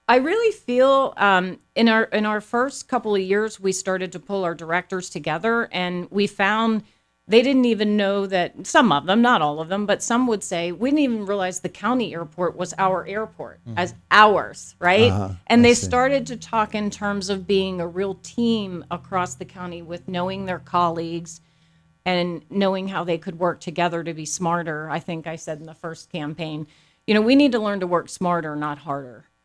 Commissioners Chairman Mike Keith and fellow Commissioner Robin Gorman appeared on Indiana in the Morning on WCCS to announce that both plan on running for a second term in office.